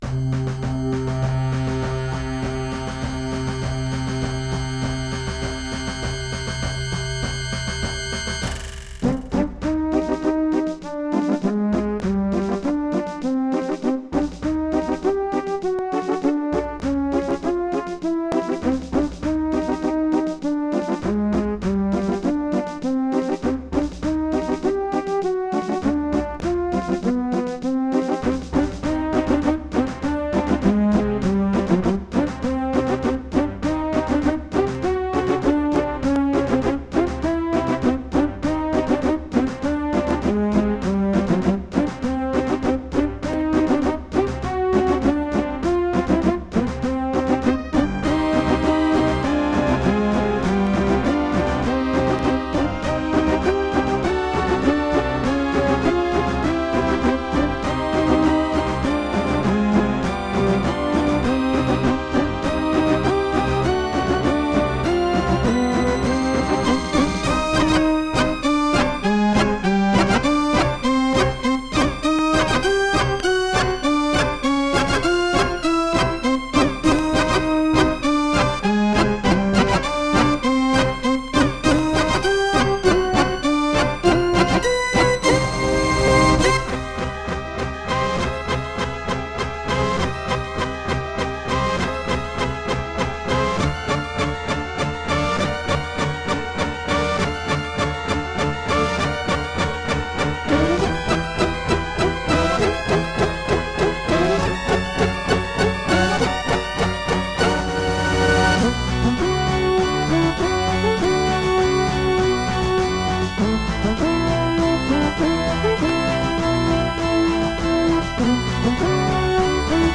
Roland LAPC-I
* Some records contain clicks.